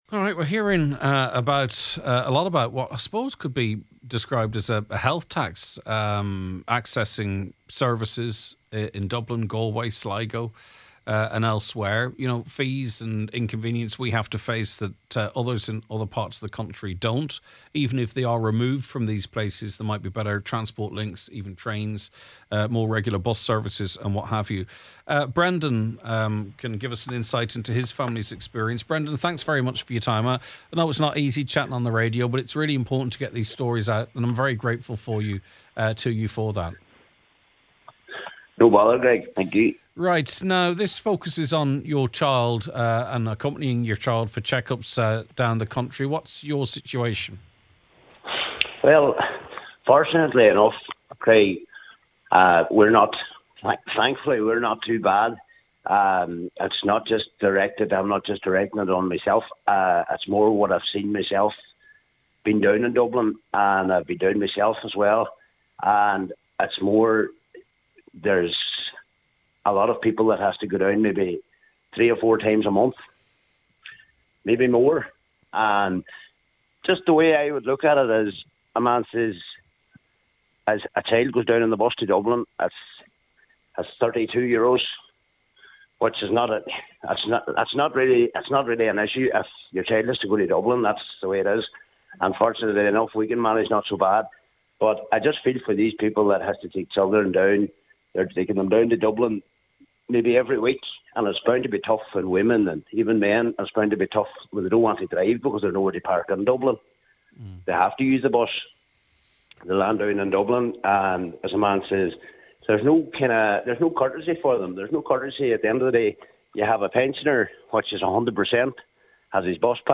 The Nine Til Noon Show is broadcast, live Weekdays from 9am to 12noon- the podcast is below: